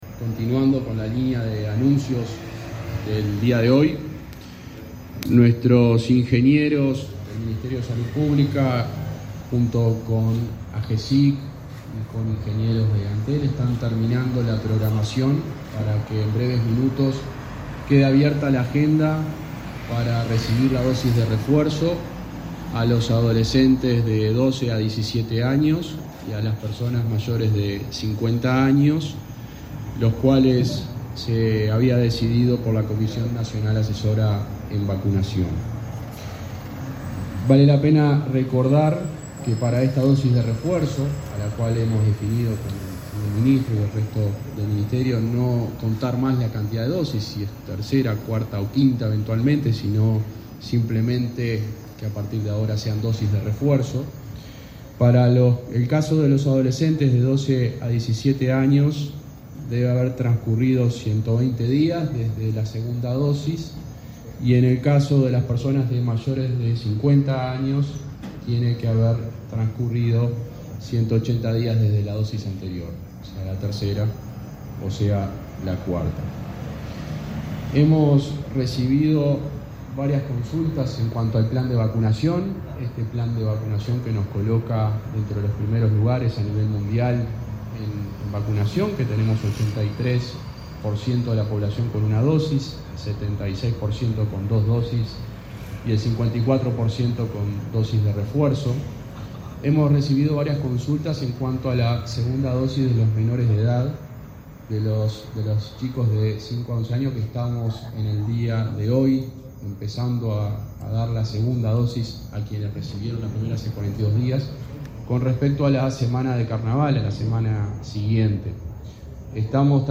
Palabras del subsecretario de Salud Pública, José Luis Satdjian